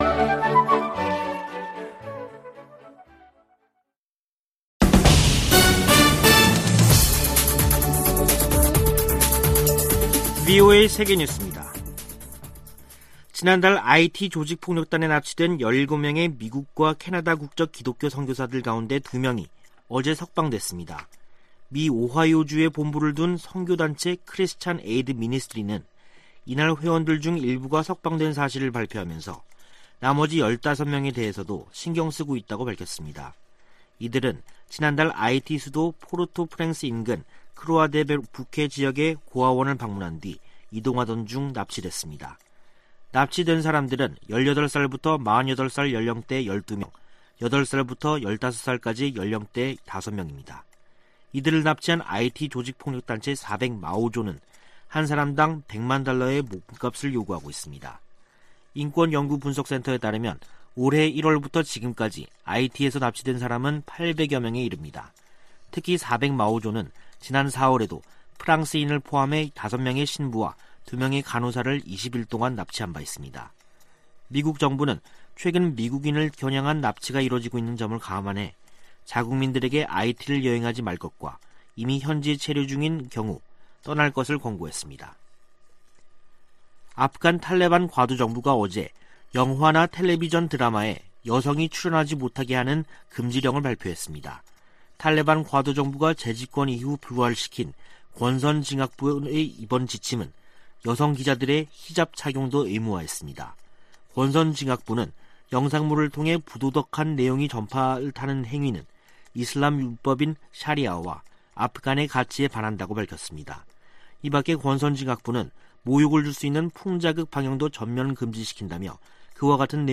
VOA 한국어 간판 뉴스 프로그램 '뉴스 투데이', 2021년 11월 22일 3부 방송입니다. 바이든 미국 대통령의 베이징 동계올림픽 외교적 보이콧 검토에 따라 한국 정부의 한반도 평화프로세스 구상에 어떤 영향이 미칠지 주목되고 있습니다. 커트 캠벨 백악관 국가안보회의 인도태평양 조정관은 바이든 정부 인도태평양 전략의 핵심 중 하나로 동맹과의 심도 있는 협력을 꼽았습니다. 미국 바이든 행정부가 인도태평양 지역에서 동맹국의 역할을 강조하며 중국 견제를 위한 움직임을 이어가고 있습니다.